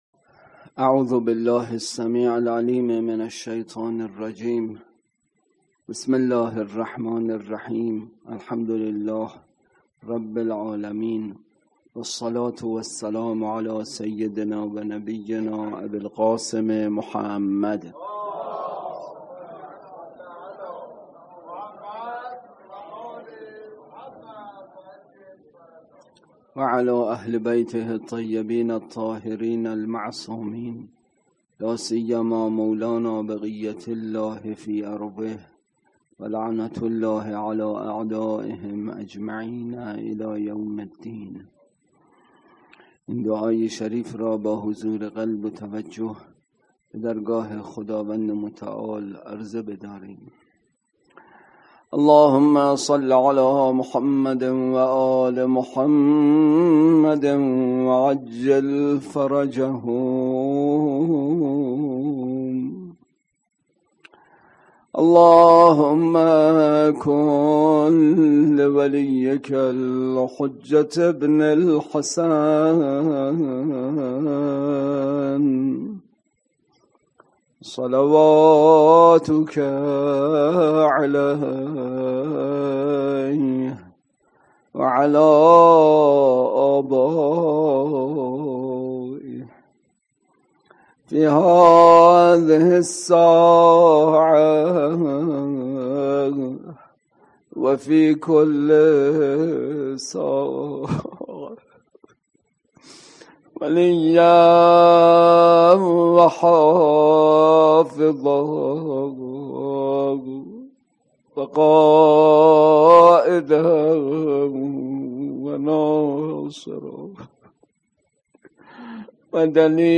اطلاعات آلبوم سخنرانی
برگزارکننده: حسینیه اهل بیت